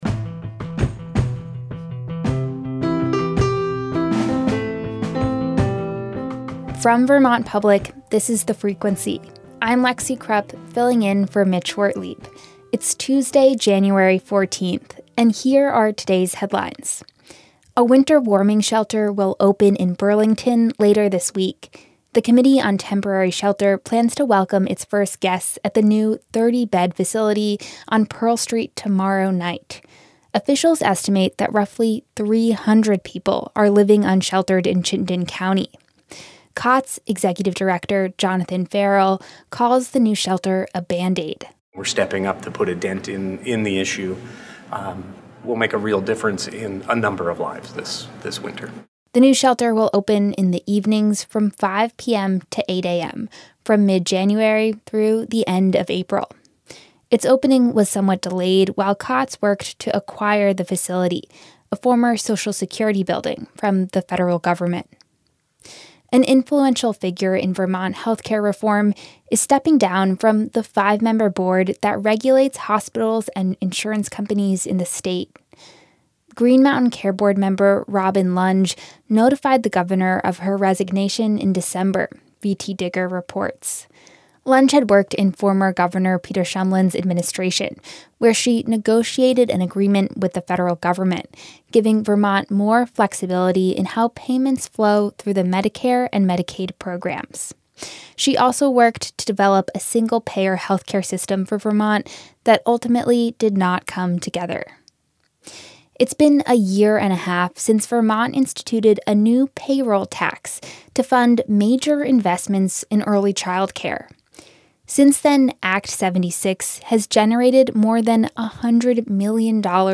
Vermont Public's daily news podcast. Get up to speed on what's happening every day in Vermont in under 15 minutes.